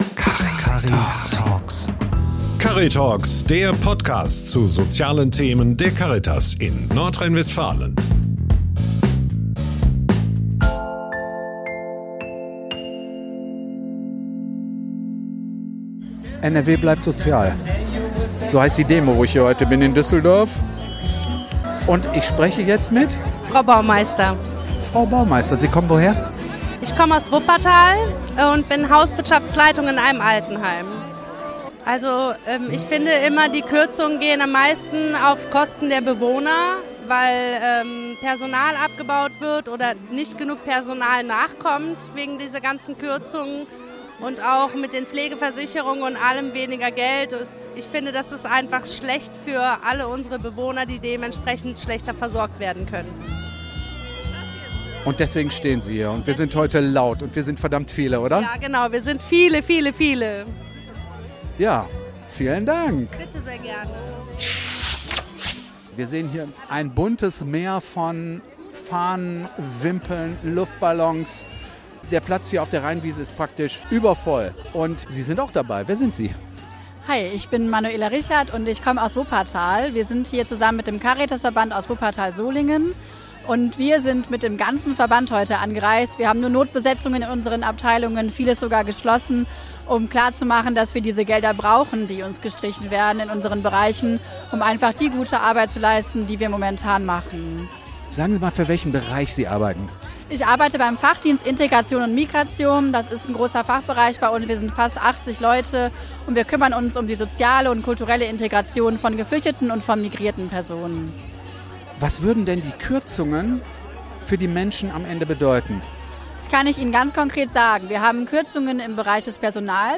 Beschreibung vor 1 Jahr Am 13. November protestierten 32.000 Menschen auf der Rheinwiese gegenüber des Düsseldorfer Landtags gegen die geplanten Kürzungen der NRW Landesregierung im sozialen Bereich. Die Wohlfahrtsverbände hatten zur Teilnahme an dieser Demo aufgerufen.